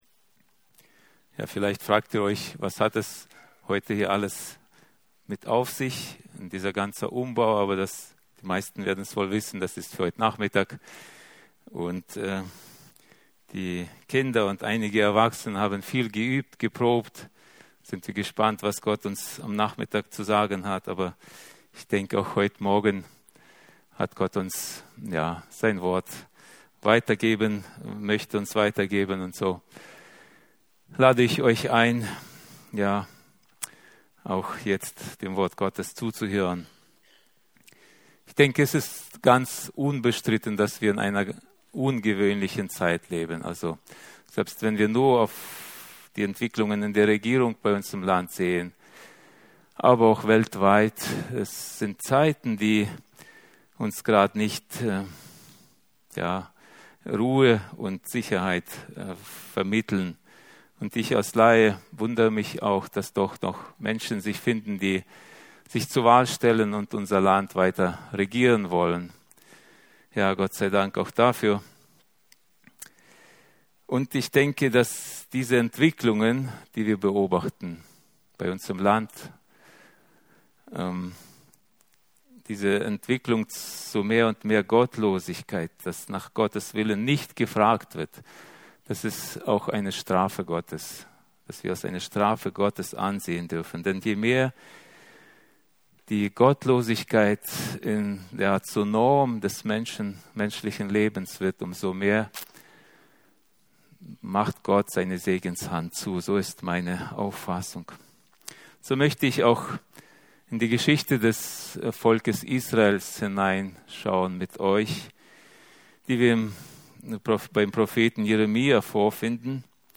Jeremia 29,4-11 Dienstart: Predigten Wir sind auf dem Weg in die Herrlichkeit 18.